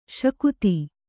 ♪ śakuti